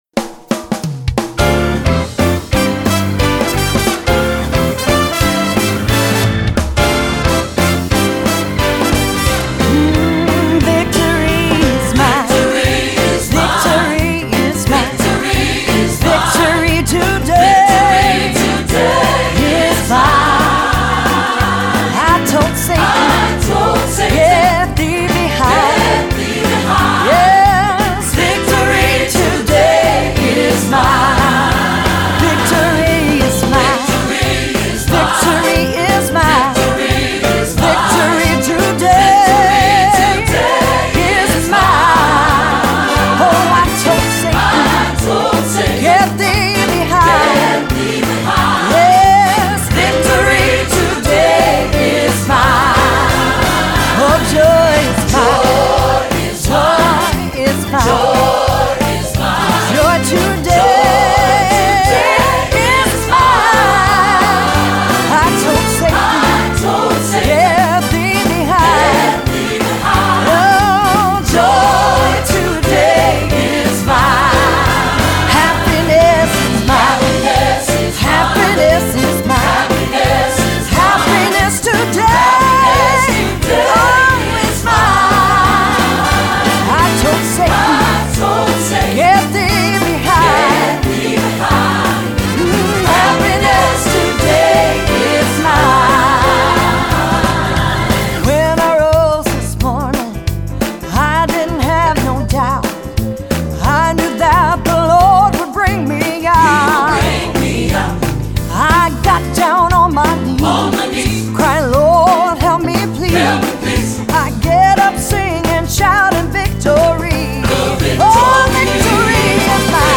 Choral Church
The excitement in this high-energy gospel setting
SATB